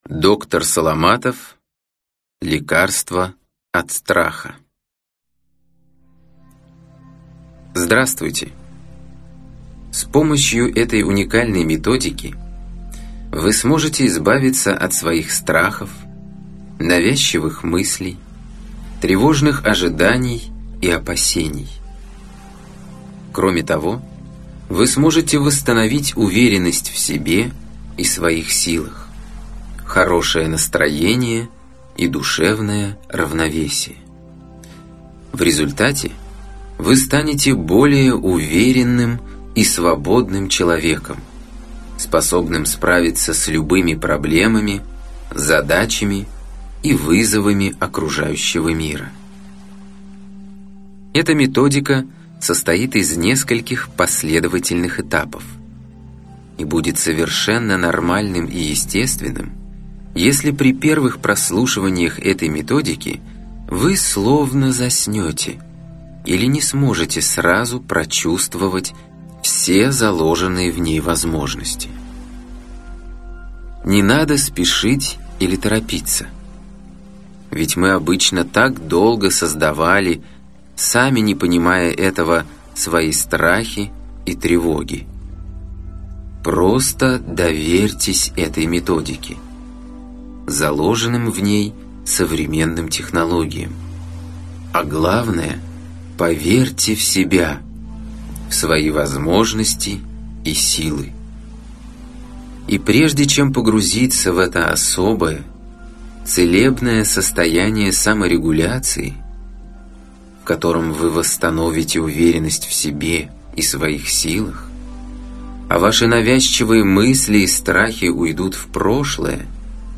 Аудиокнига Лекарство от страха | Библиотека аудиокниг